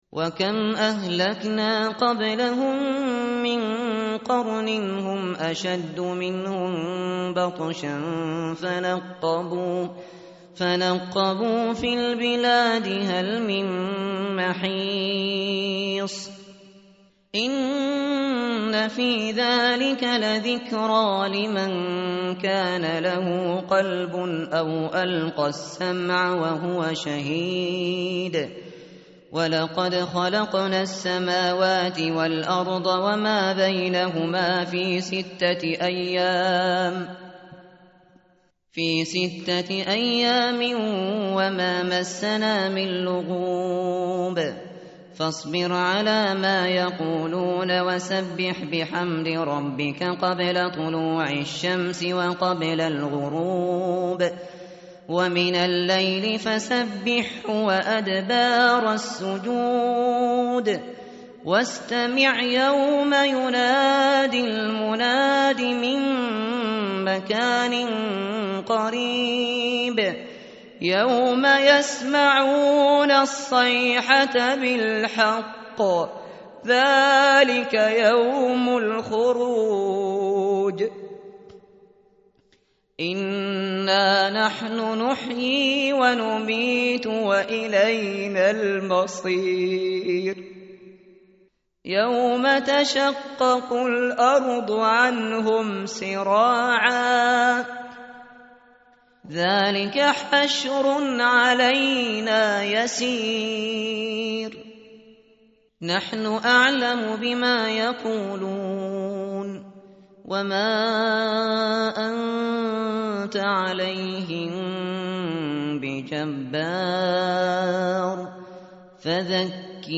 tartil_shateri_page_520.mp3